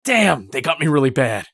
eft_usec_wound9.mp3